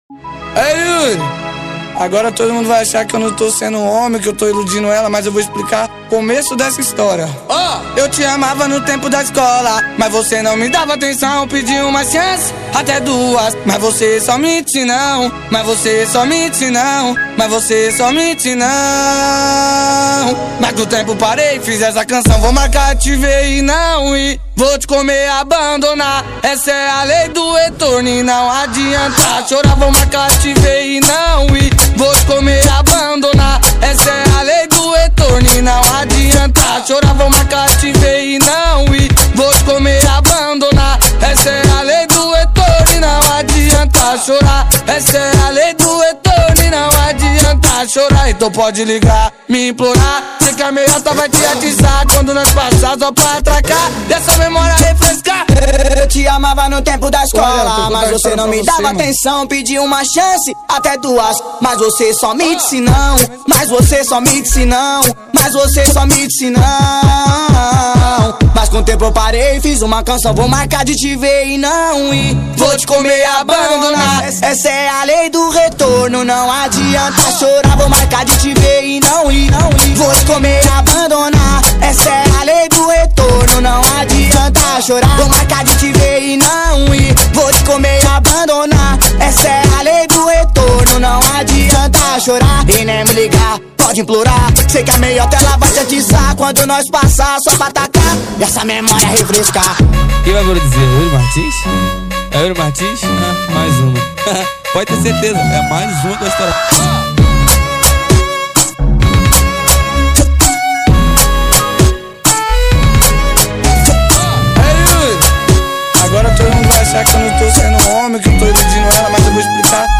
2024-12-23 20:02:53 Gênero: MPB Views